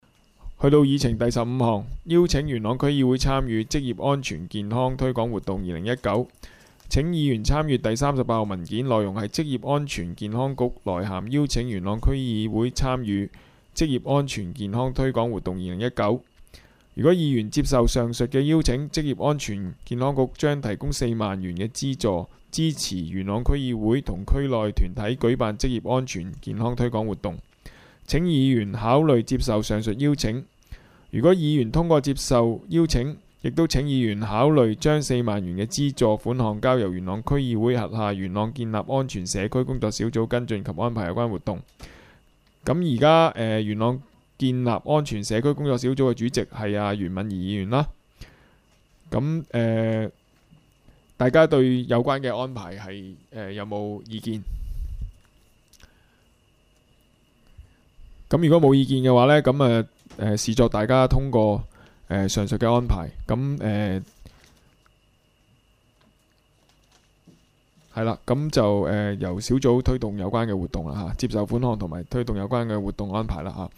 区议会大会的录音记录
元朗区议会第二次会议
地点: 元朗桥乐坊2号元朗政府合署十三楼会议厅